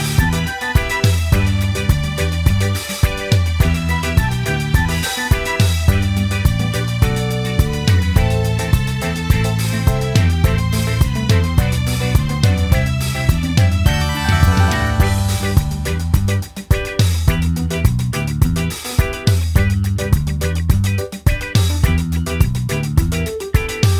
no Backing Vocals Disco 3:19 Buy £1.50